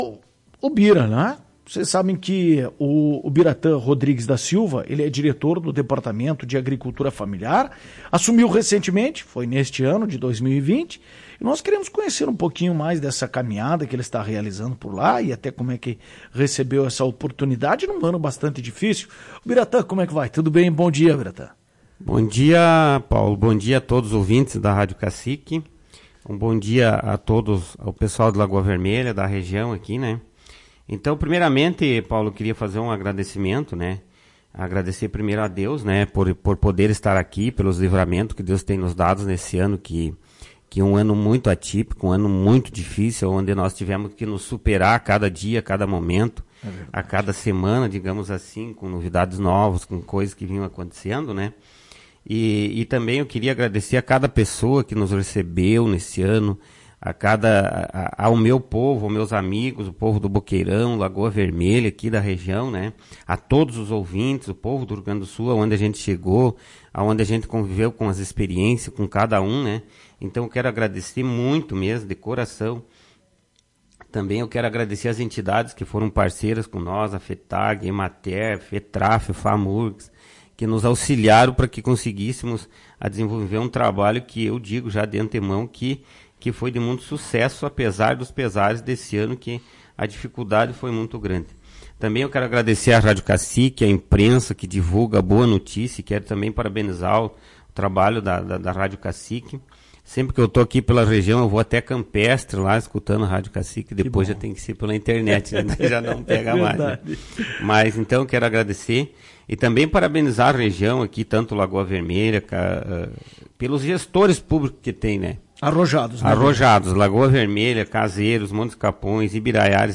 Em entrevista à Tua Rádio Cacique, na manhã desta segunda-feira (28), Obiratan contou como surgiu a oportunidade de estar frente a pasta e avaliou o trabalho realizado durante o período.